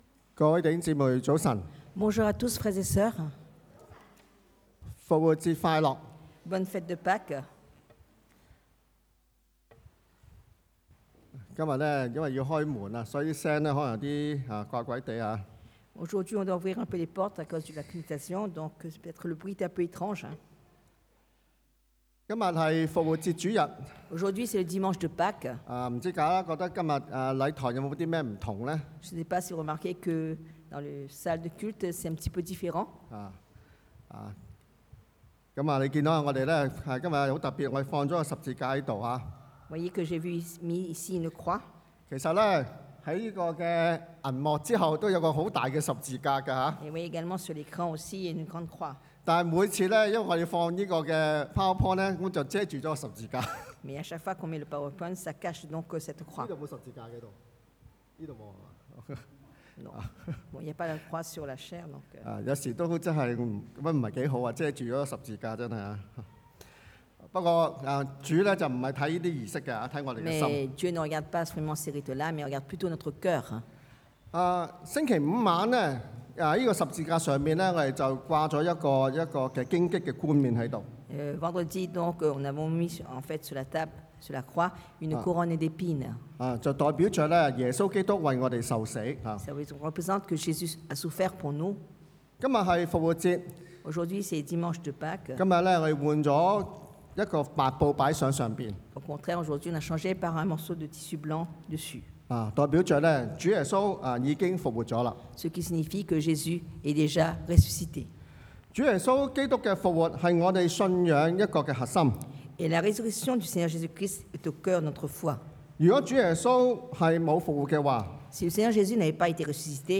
Vivre en Jésus 活在耶穌裡 – Culte du dimanche